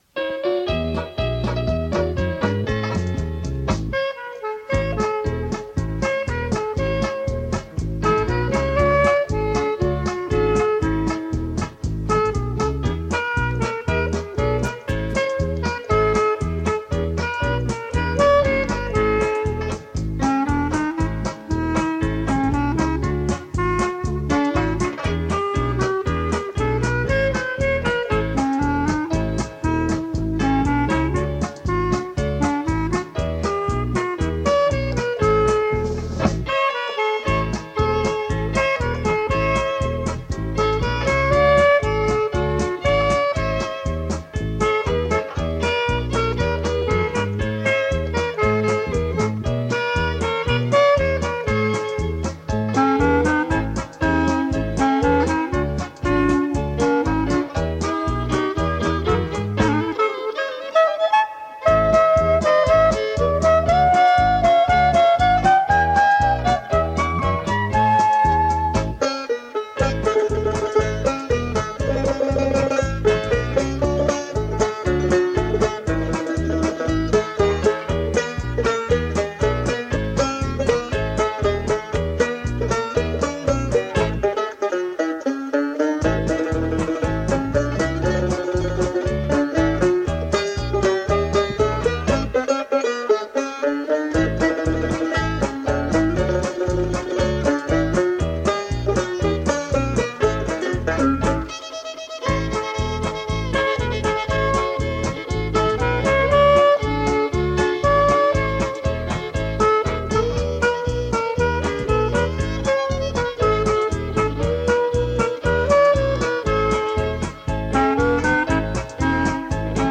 rahvatantsuseltsi "Kandali" tantsud muusikas: